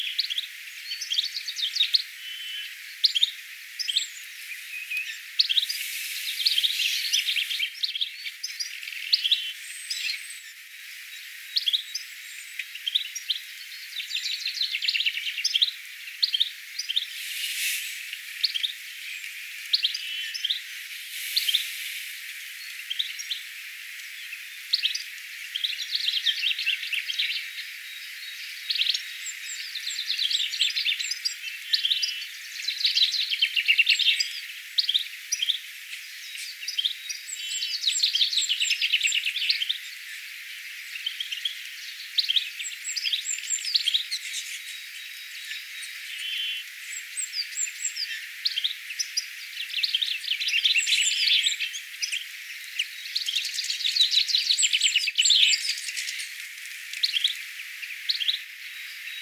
tiklin ääntelyä
tiklin_aantelya.mp3